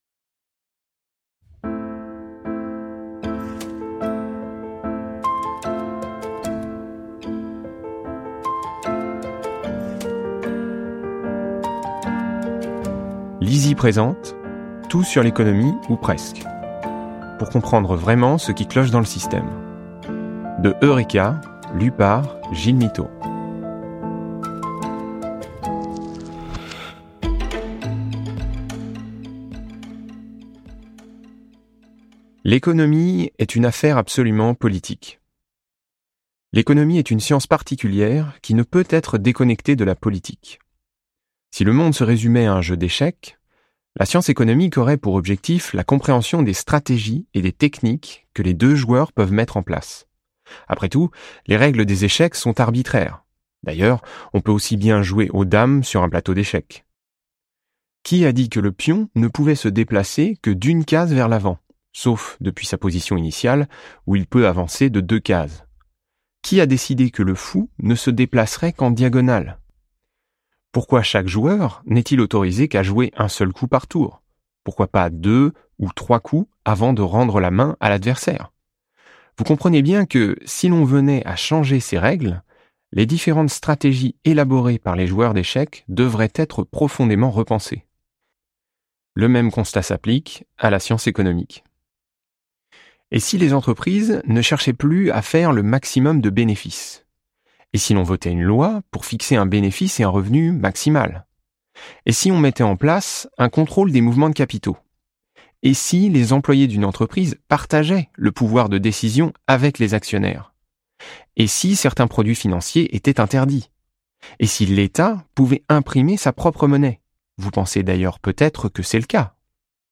Click for an excerpt - Tout sur l'économie, ou presque de Gilles MITTEAU